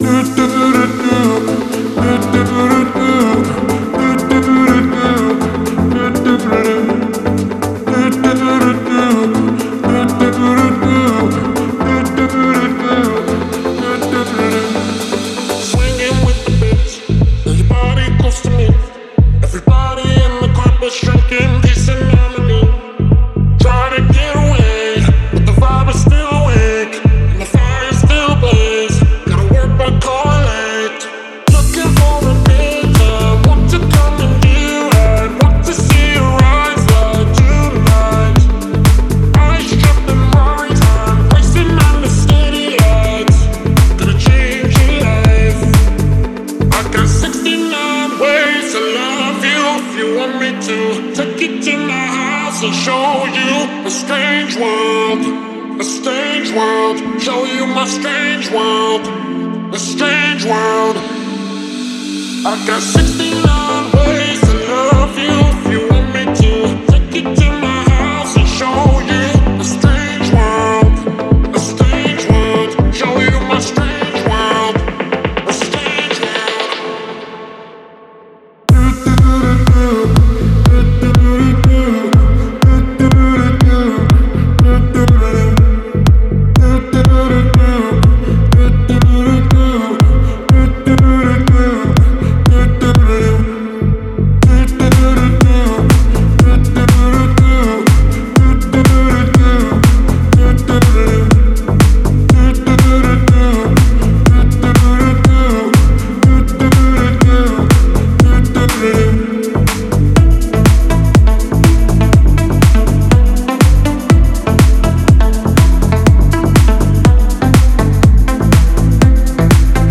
в жанре электронной музыки с элементами синти-попа